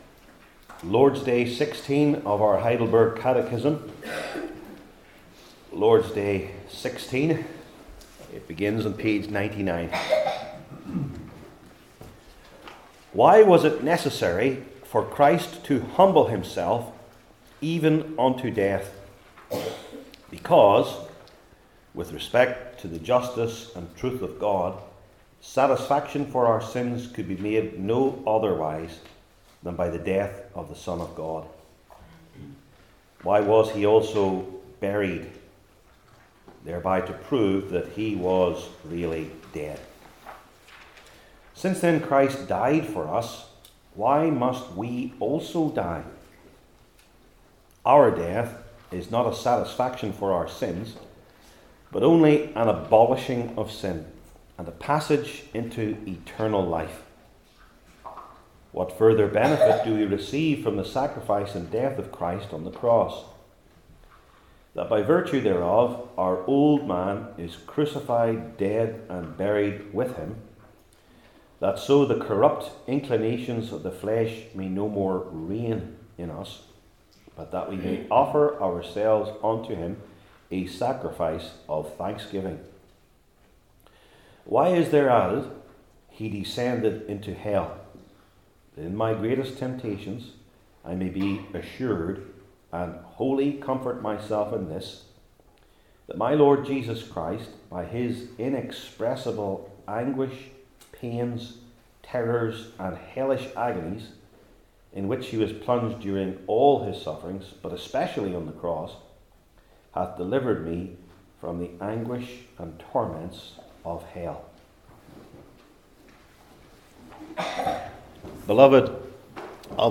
Heidelberg Catechism Sermons I. Satisfaction for Our Sins?